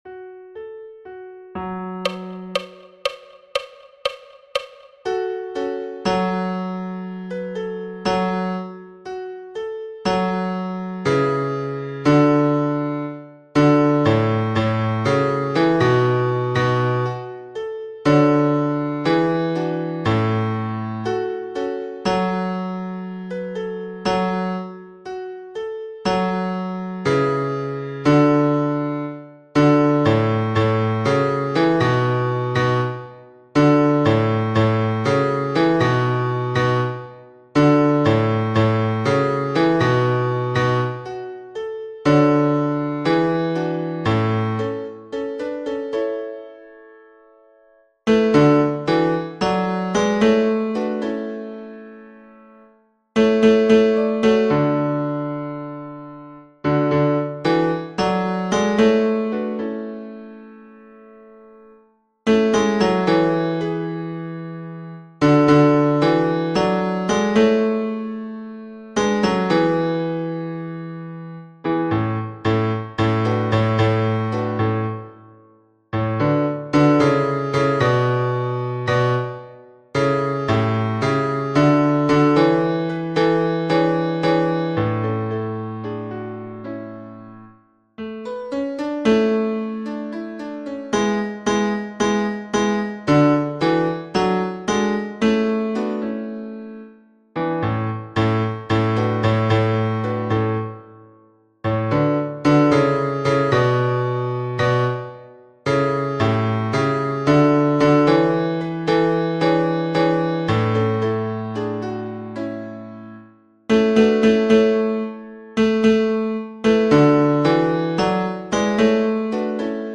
storm-bass.mp3